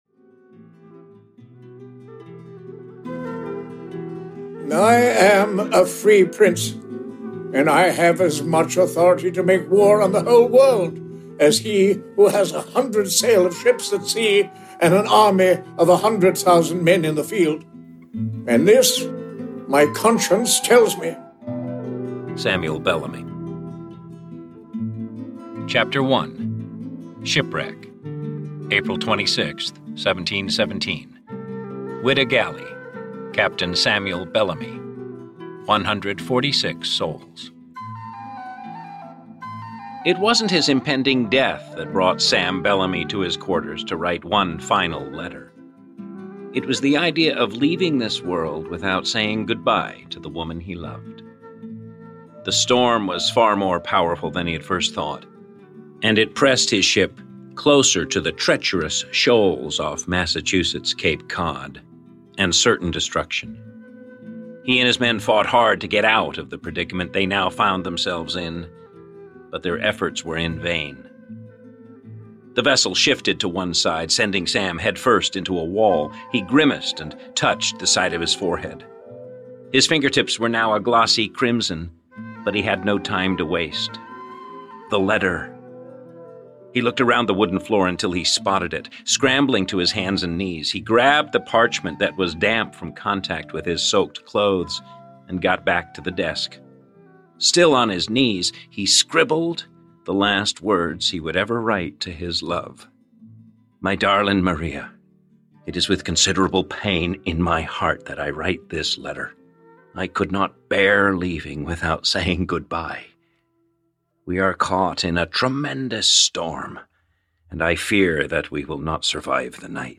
Black Sam (EN) audiokniha
Ukázka z knihy
• Interpret- Multi-Cast